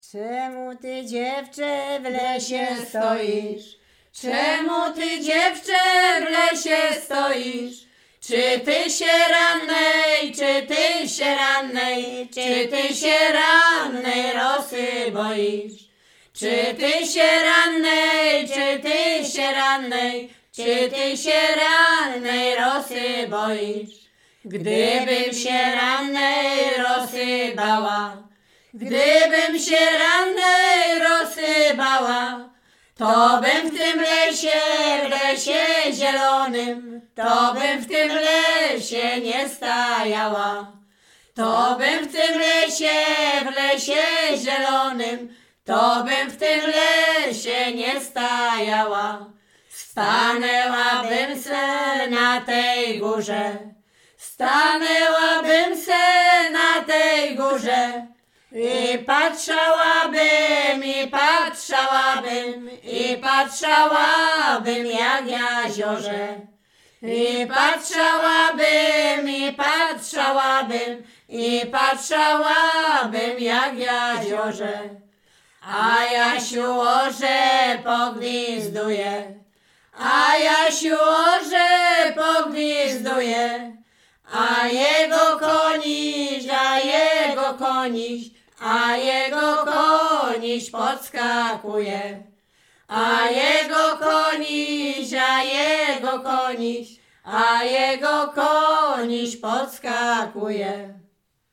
Śpiewaczki z Mroczek Małych
województwo łodzkie, powiat sieradzki, gmina Błaszki, wieś Mroczki Małe
liryczne miłosne